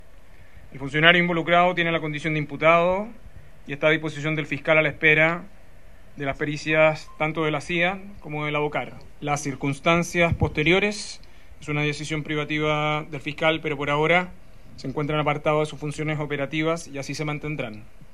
Ante aquello, el ministro de Seguridad Pública, Luis Cordero, señaló que ambos quedaron en calidad de imputados y cesados de sus funciones.